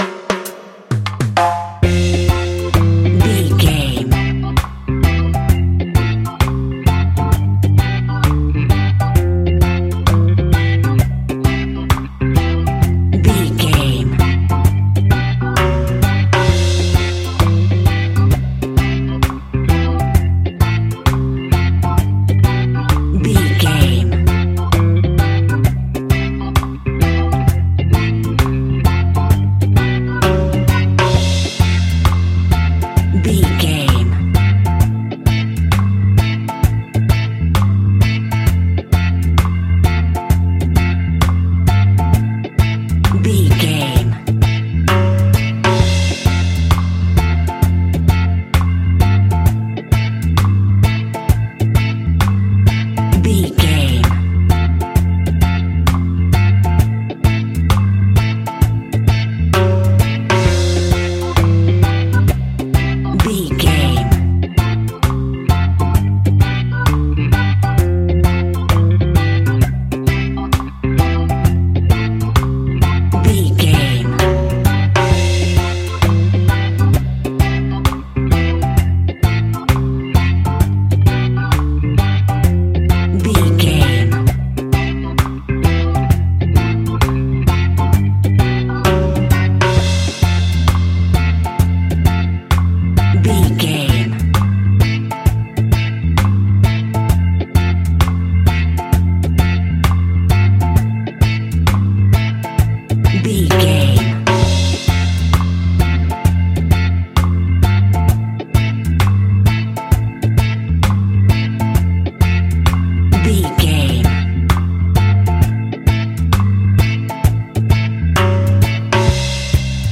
Classic reggae music with that skank bounce reggae feeling.
Ionian/Major
instrumentals
laid back
chilled
off beat
drums
skank guitar
hammond organ
percussion
horns